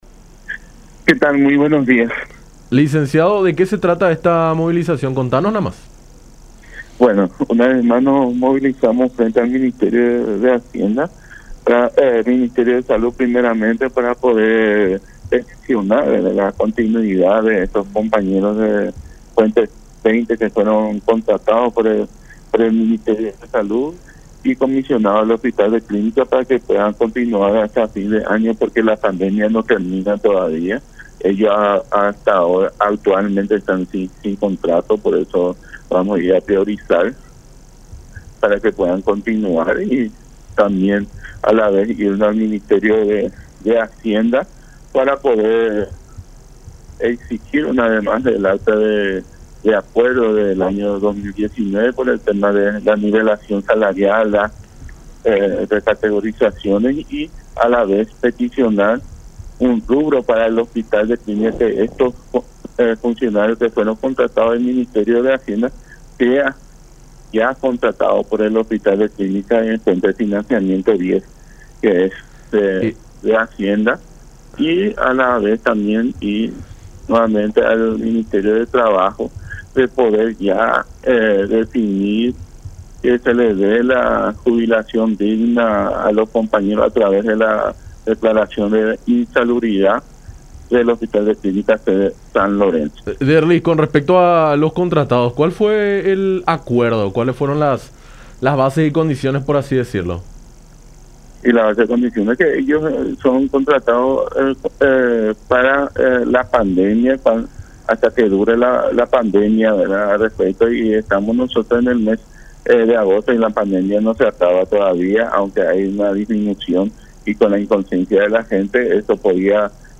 en diálogo con Enfoque 800